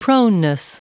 Prononciation du mot proneness en anglais (fichier audio)
Prononciation du mot : proneness